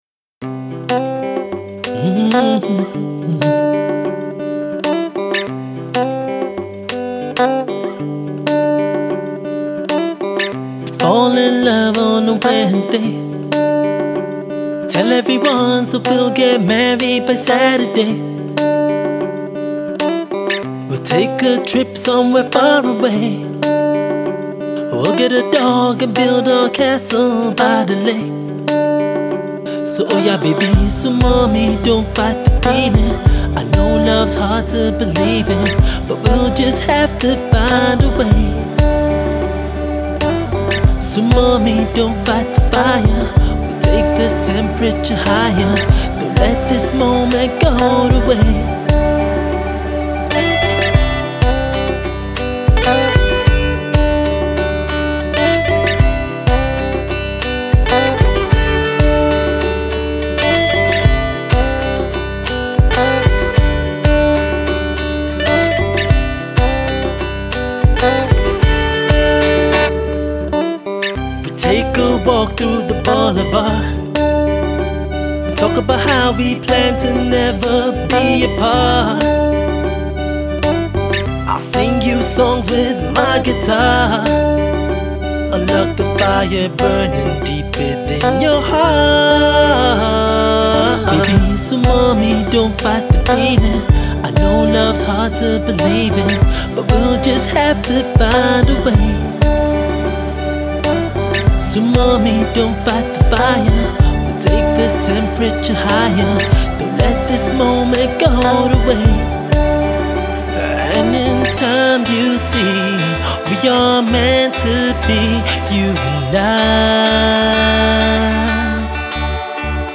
touching love ballad